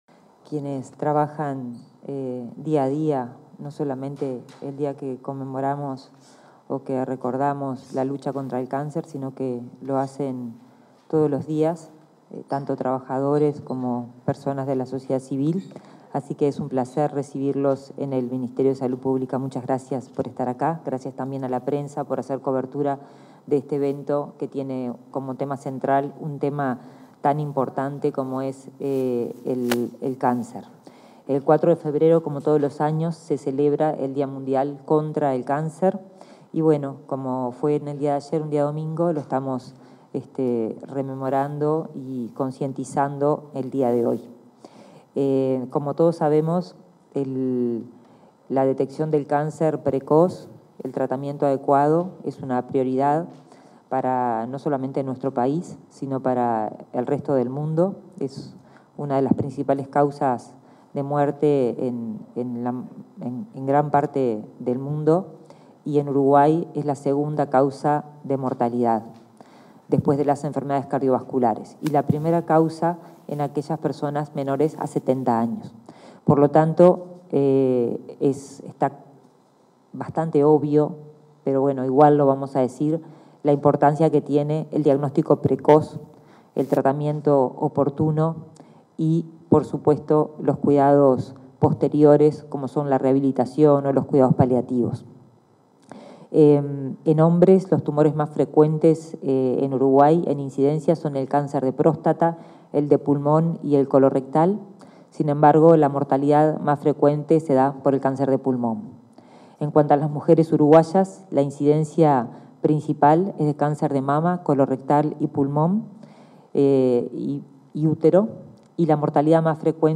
Palabras de la ministra de Salud Pública, Karina Rando
En el marco del acto por el Día Mundial contra el Cáncer, este 5 de febrero, se expresó la ministra de Salud Pública, Karina Rando.